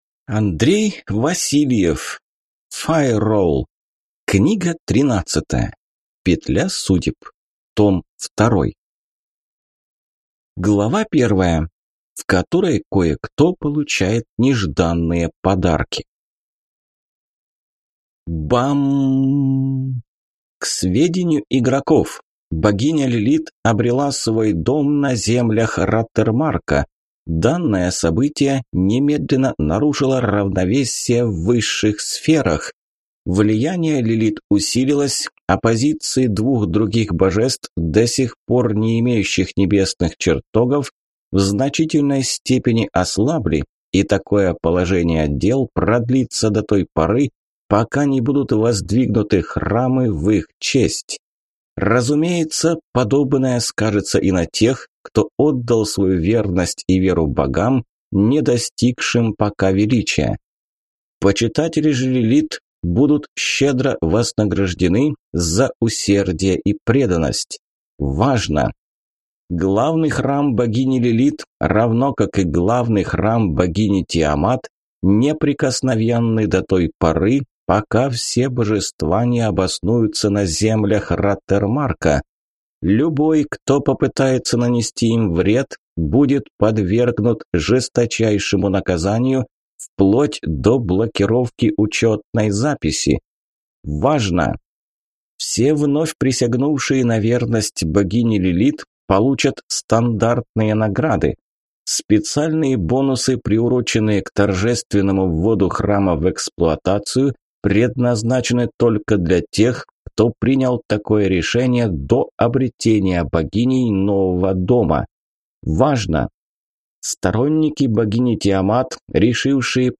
Аудиокнига Файролл. Петля судеб. Том 2 | Библиотека аудиокниг